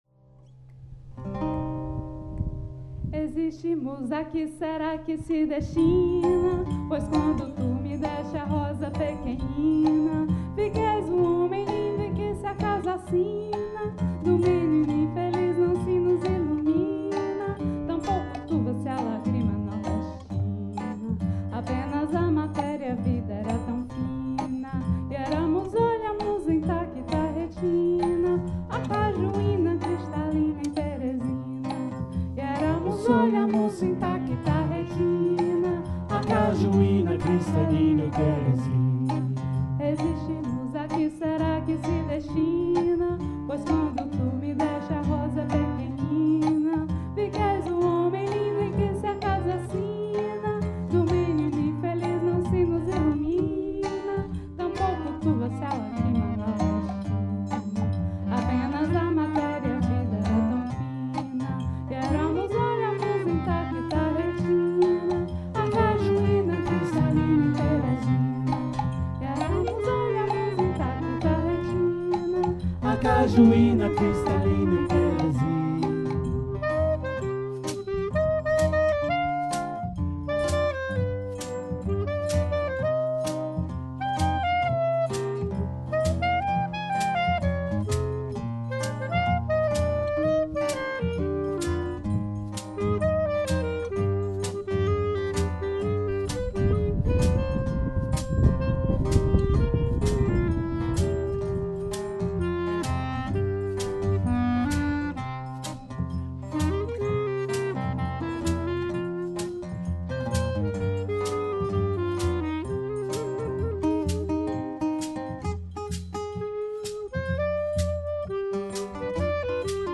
Joué pour la fête de la Musique 2024 au CE TAS